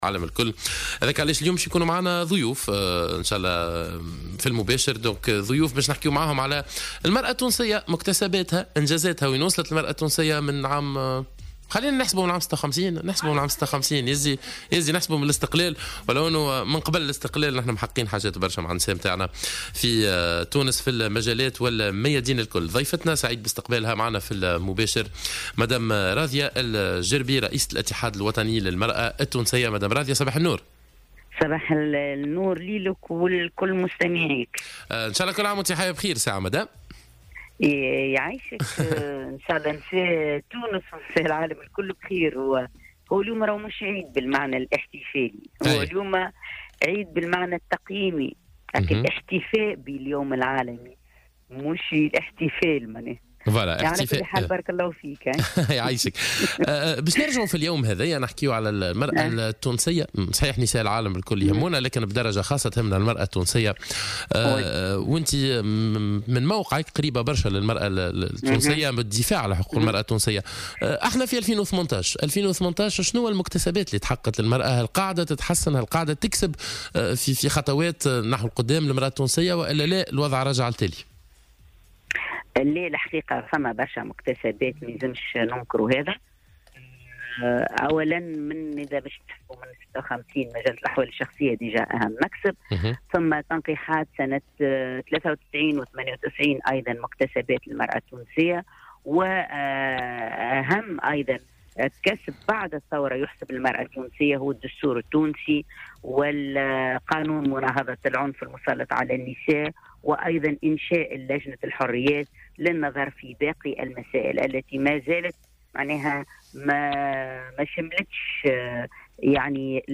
وأكدت في برنامج "صباح الورد" على "الجوهرة أف أم" انه تحققت مكتسبات تشريعية مهمة لفائدة المرأة التونسية على غرار مجلة الأحوال الشخصية منذ عام 1956 وأيضا اقرار تنقيحات لفائدة المرأة سنة 1993 و1998 كما تم بعد الثورة اصدار الدستور التونسي وقانون مناهضة العنف ضد النساء واحداث لجنة الحريات للنظر في باقي المسائل حول المساواة. وأضافت أنه رغم كل هذه المكتسبات يبقى العمل على تغيير العقليات تجاه المرأة بفضل عمل القيادات.